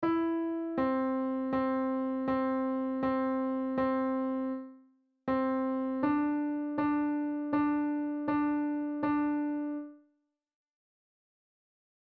On the piano, play The Grand Old Duke Of York